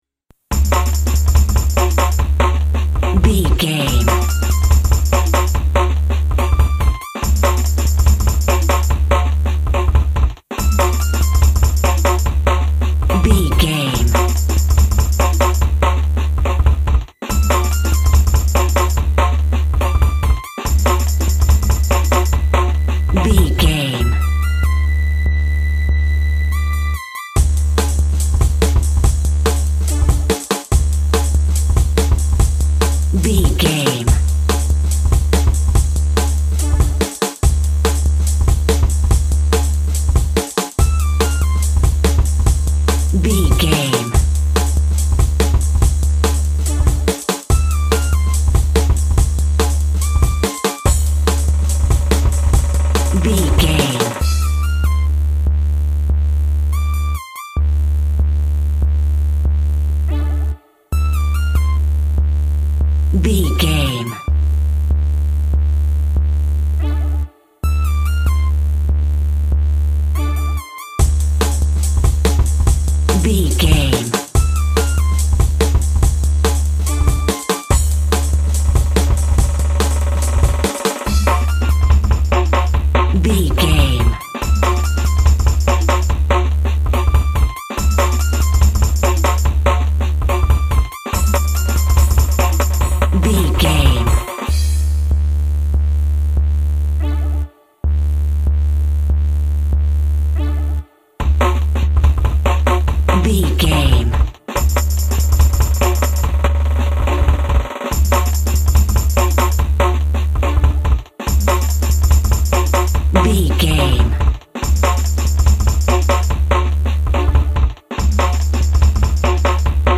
Space Techno.
Aeolian/Minor
driving
energetic
futuristic
hypnotic
dark
drums
synthesiser
dance instrumentals
synth lead
synth bass